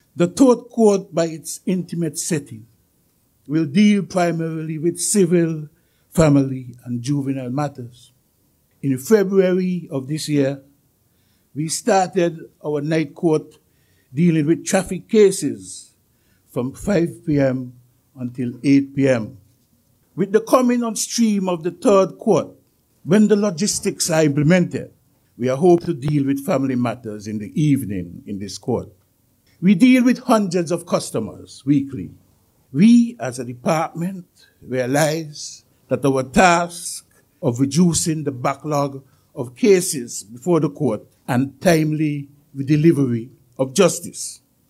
An opening ceremony for a new court annex the Sir Lee L. Moore judicial complex was held on Tuesday, December 14th 2021.
During remarks made at the opening ceremony Senior Magistrate Ag. Mr. Renold Benjamin gave this synopsis on the matters the new court will address: